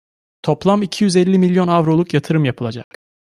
Pronounced as (IPA) /ja.tɯ.ɾɯm/